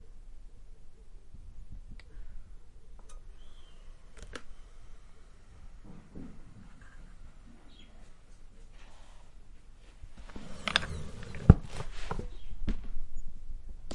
椅子
描述：这是一个横跨瓷砖地板的酒吧椅，营造出相当刺耳的声音
Tag: 滑动 椅子 混凝土